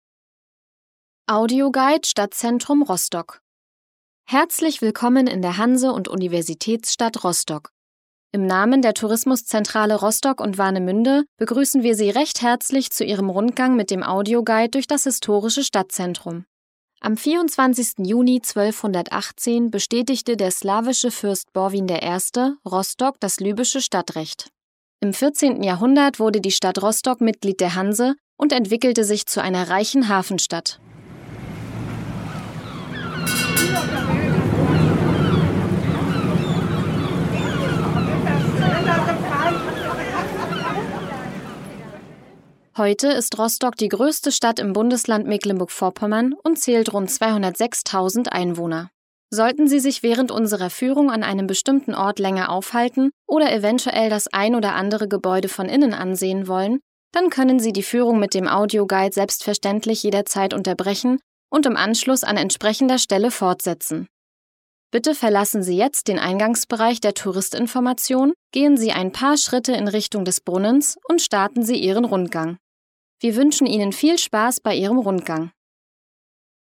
Audioguide Rostock - Station 1: Einleitung & Rostock-Information